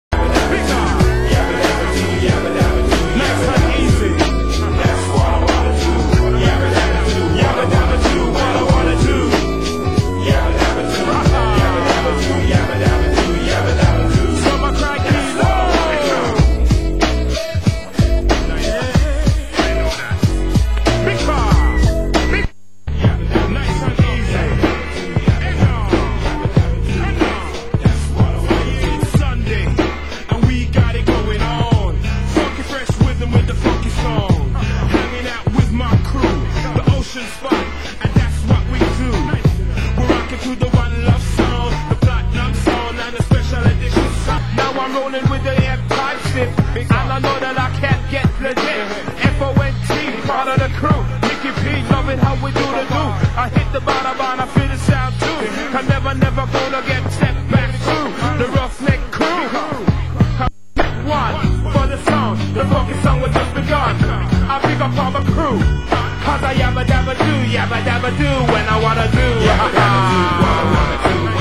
Genre: Hip Hop